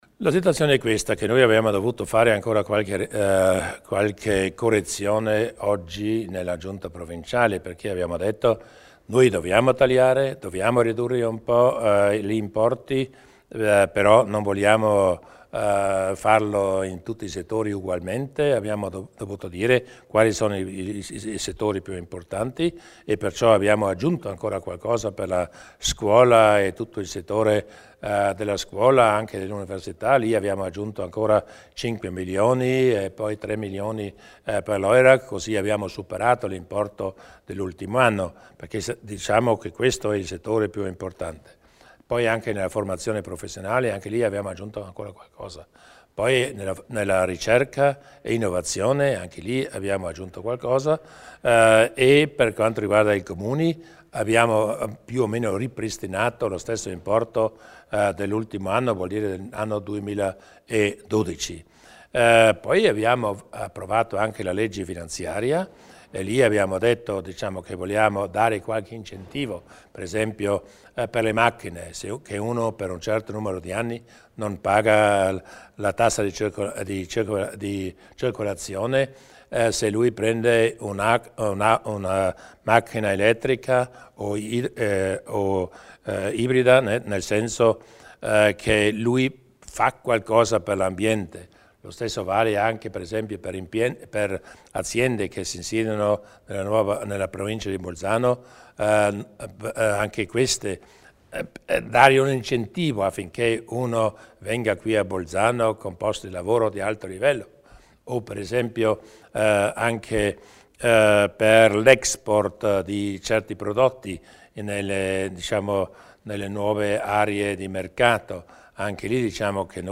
Il Presidente Durnwalder elenca le priorità del bilancio 2013